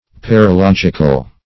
Search Result for " paralogical" : The Collaborative International Dictionary of English v.0.48: Paralogical \Par`a*log"ic*al\, a. Containing paralogism; illogical.